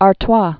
(är-twä)